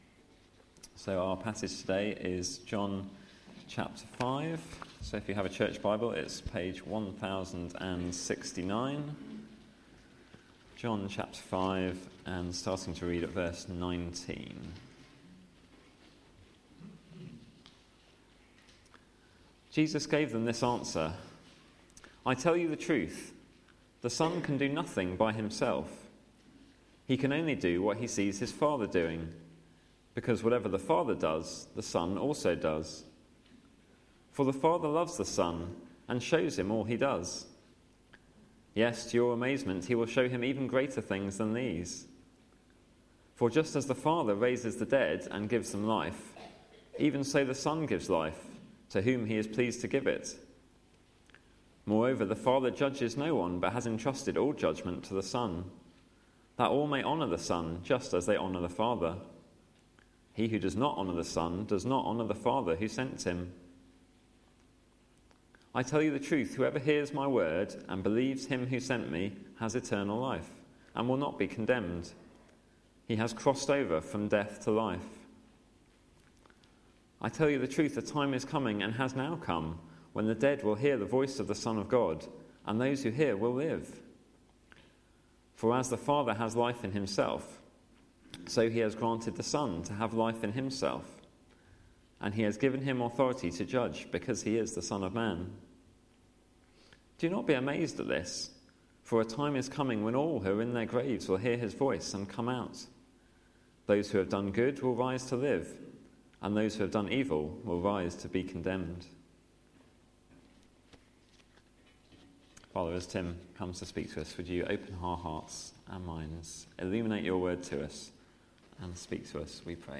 Media for Sunday Service on Sun 22nd Nov 2015 10:00
Theme: I do only what I see the father doing Sermon